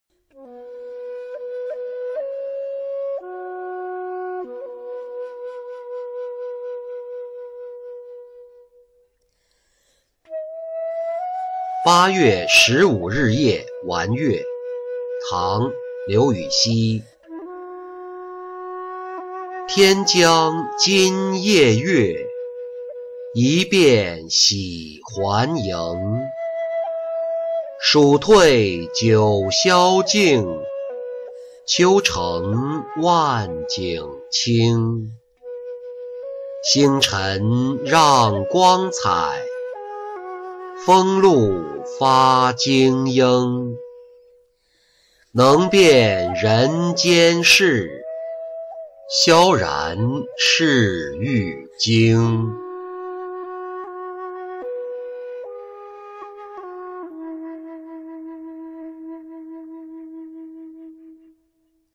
八月十五日夜玩月-音频朗读